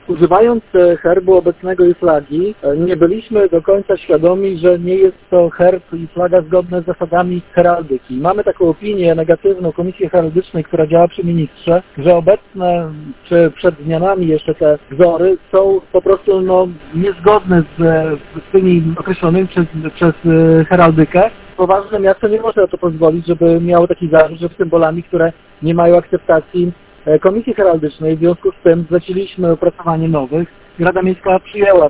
– Jako poważny samorząd, nie mogliśmy posługiwać się symbolami, które nie spełniają wymagań heraldycznych – mówi burmistrz Giżycka, Wojciech Karol Iwaszkiewicz.
burmistrz-giżycka.mp3